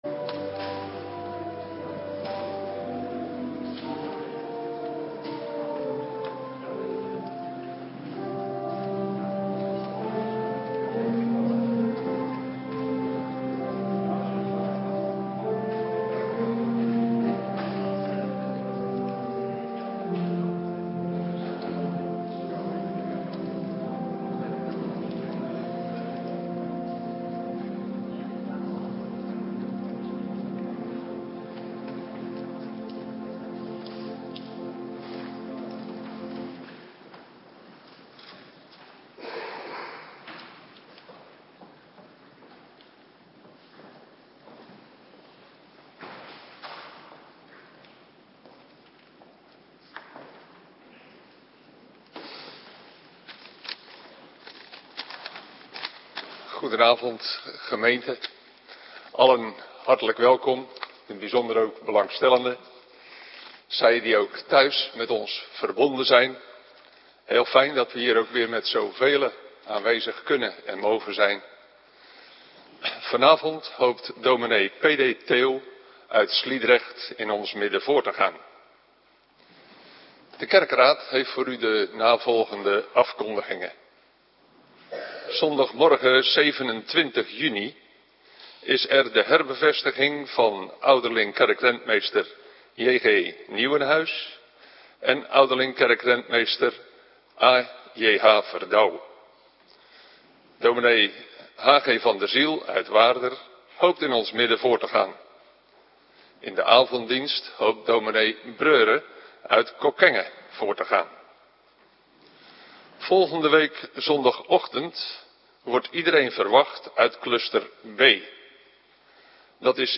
Avonddienst - Cluster B